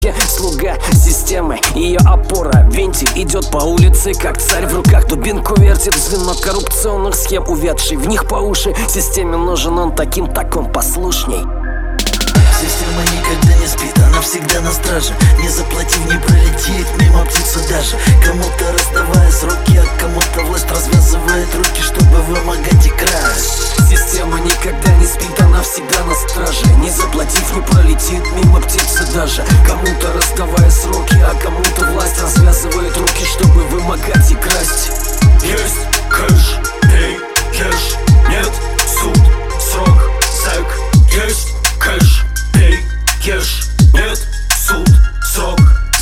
Нужна оценка сведения и мастеринга трека.